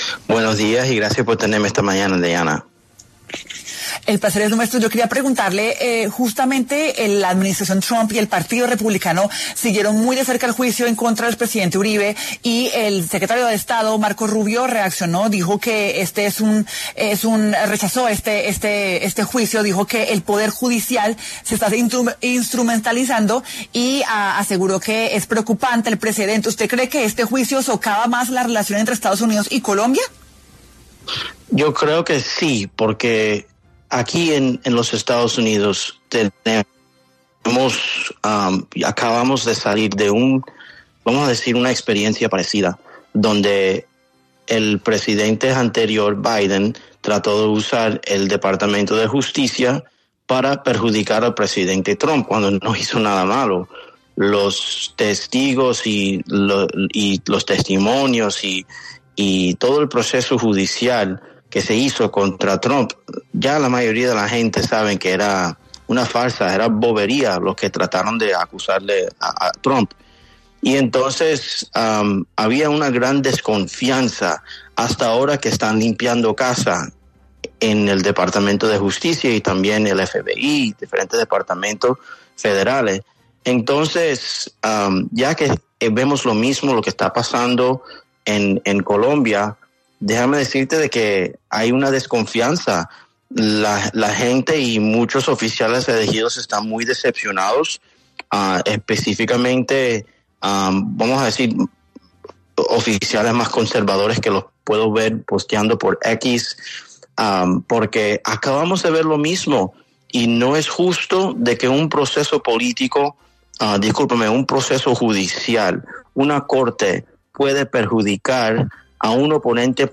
En una reciente entrevista con 6AM de Caracol Radio, David Borrero, político estadounidense, compartió sus perspectivas sobre la reacción en Estados Unidos al caso del expresidente Álvaro Uribe Vélez.